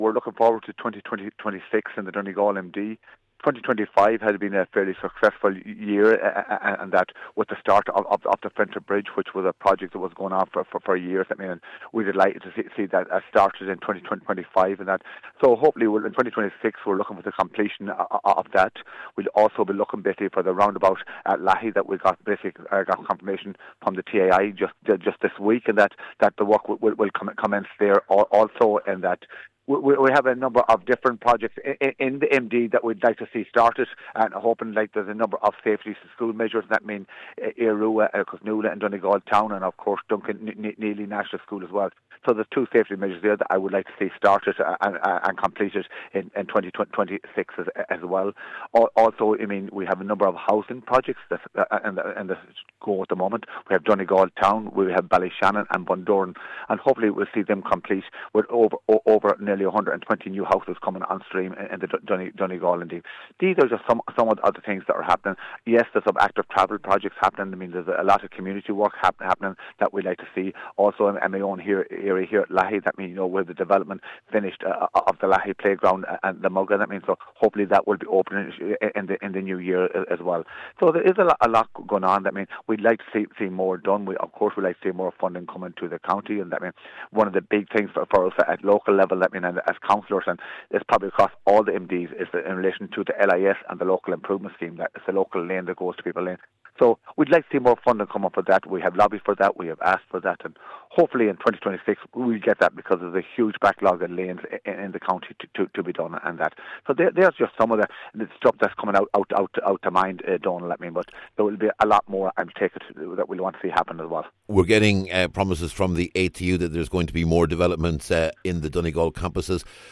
Speaking to Highland Radio News about his priorities for the coming year, Cllr Michael Naughton said the work being done with Derry and Strabane in terms of advancing the North West City Gateway is very important, and he wants to see that replicated in the south of the county.